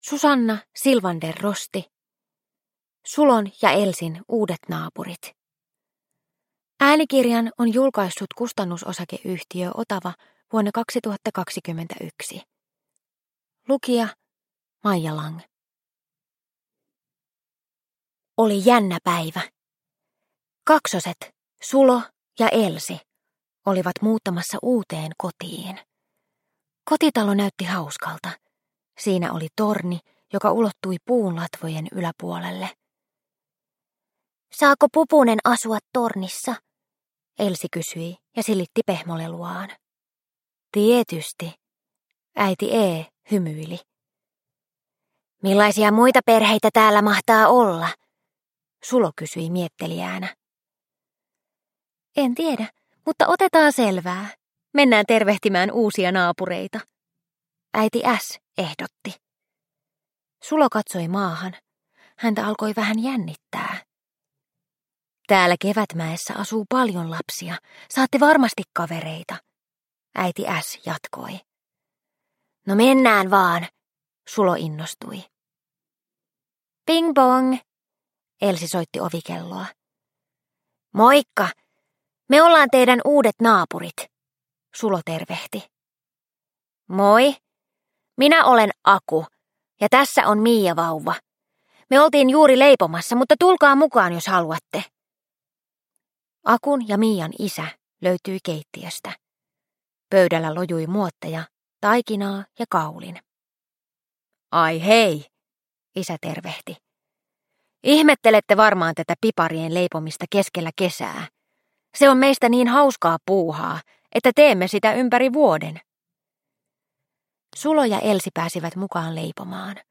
Sulon ja Elsin uudet naapurit – Ljudbok – Laddas ner